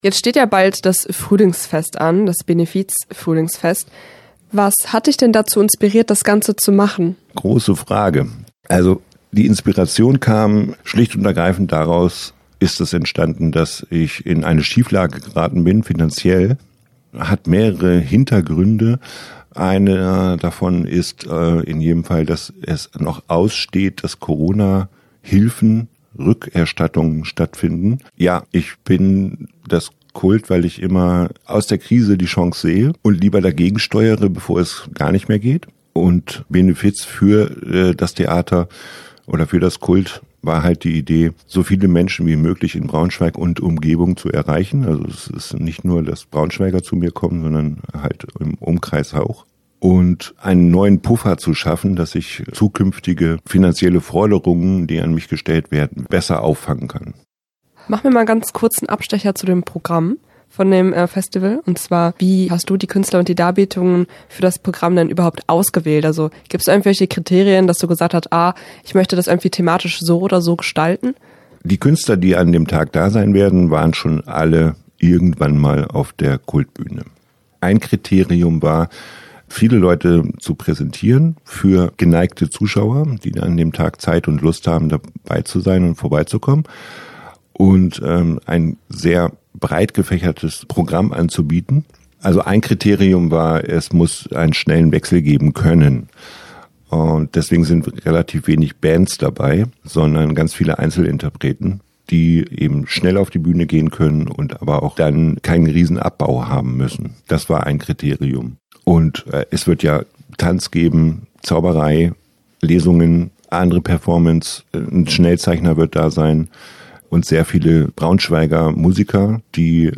Interview-KULT-Benefizfest_jg.mp3